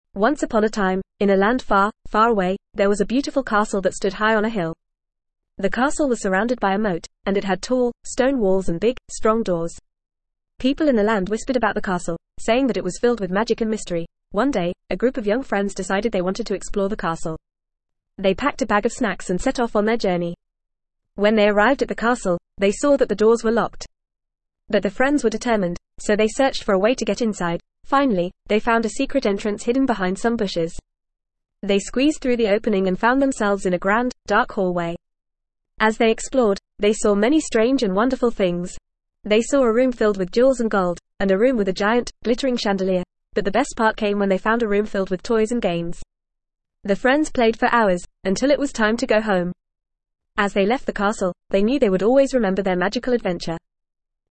Fast
ESL-Short-Stories-for-Kids-FAST-reading-The-Mysterious-Castle.mp3